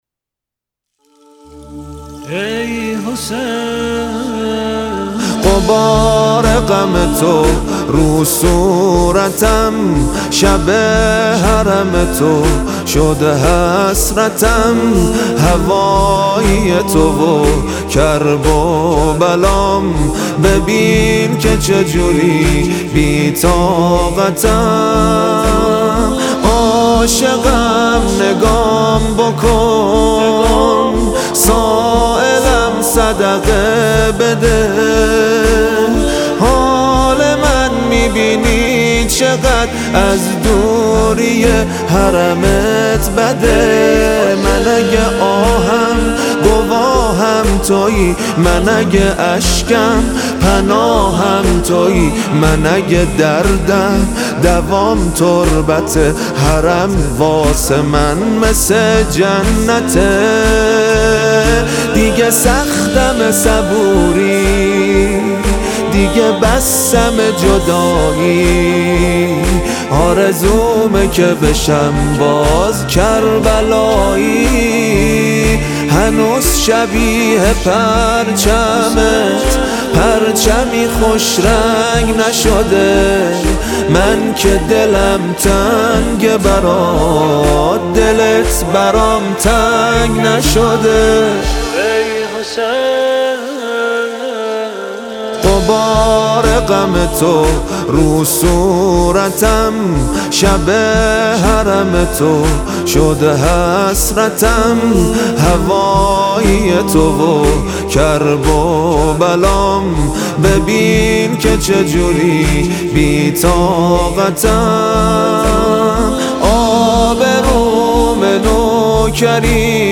ضبط: استودیو نور یاس